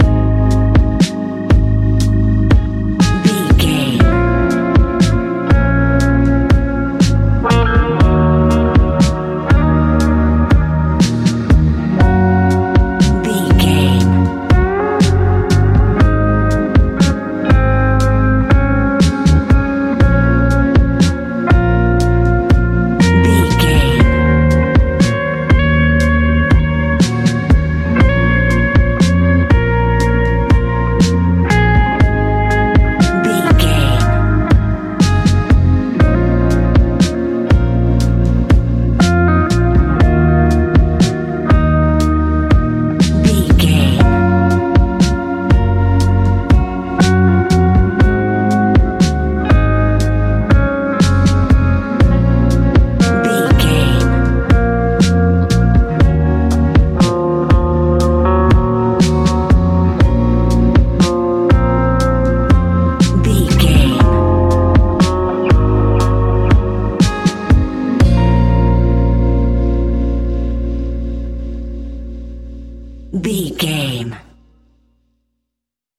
Ionian/Major
A♯
chill out
laid back
Lounge
sparse
new age
chilled electronica
ambient
atmospheric
morphing